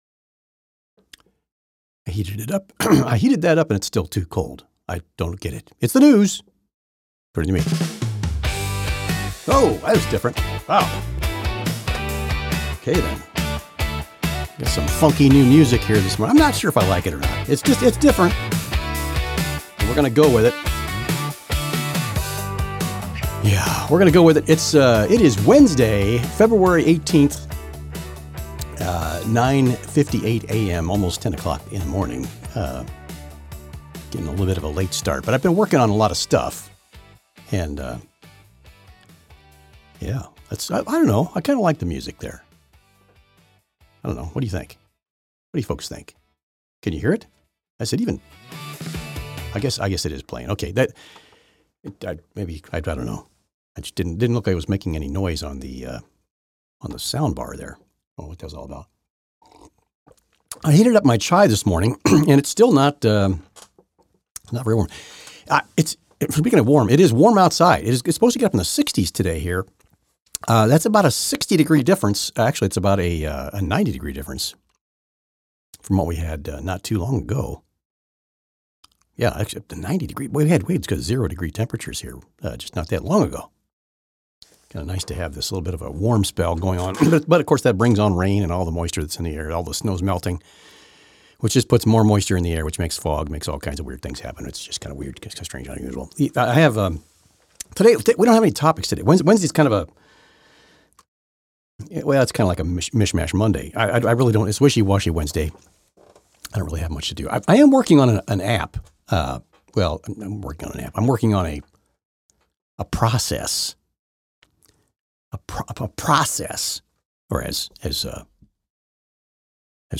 A little fun with the AI callers again.